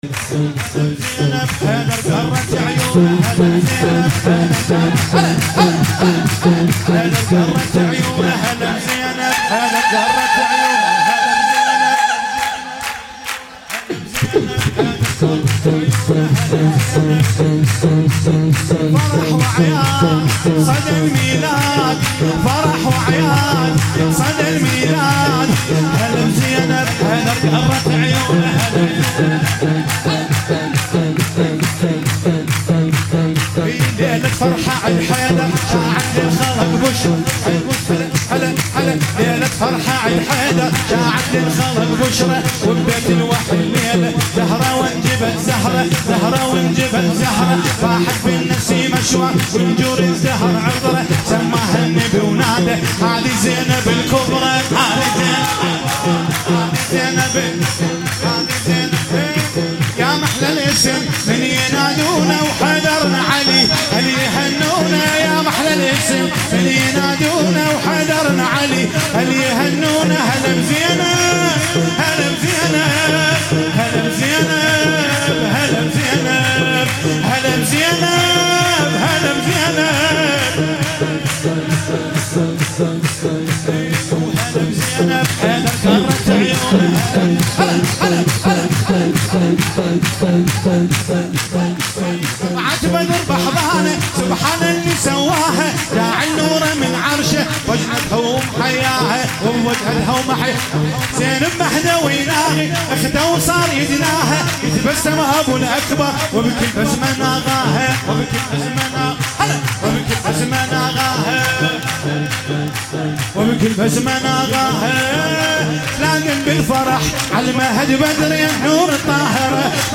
ولادت حضرت زینب سلام الله علیها
سرود عربی